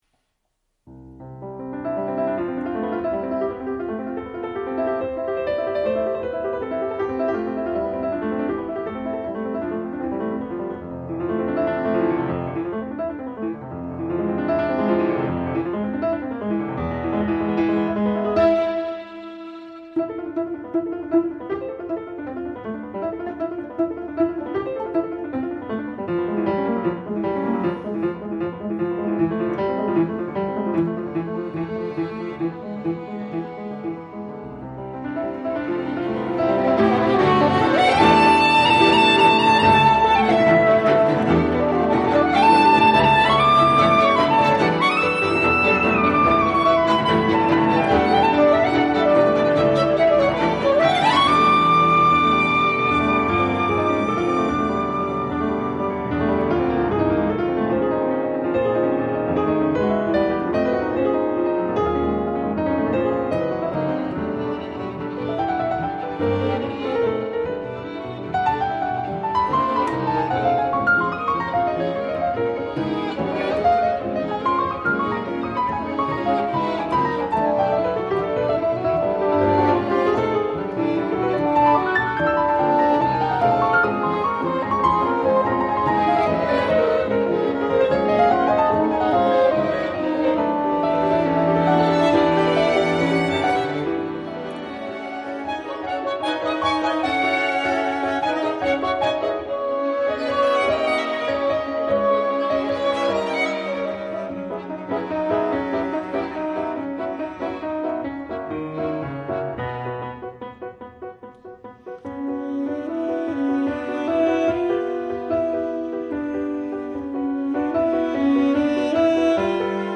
music for piano, saxophone and string quartet
The group recorded a special BBC radio 3 session